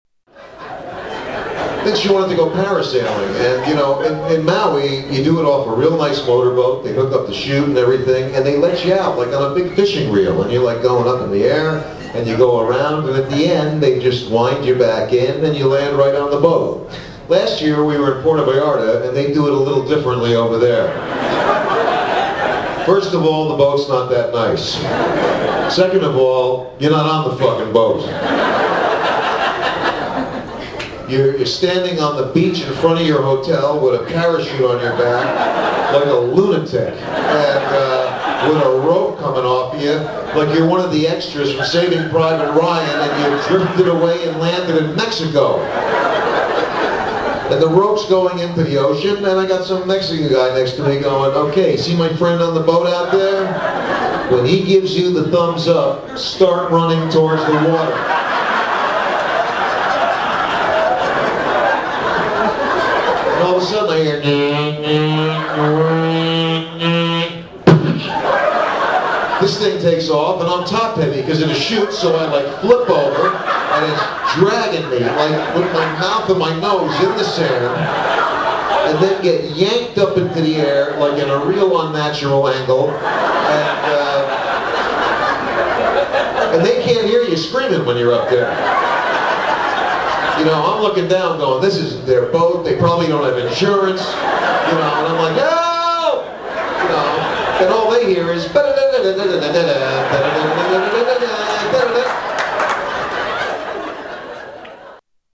Tags: Comedian Robert Schimmel clips Robert Schimmel audio Stand-up comedian Robert Schimmel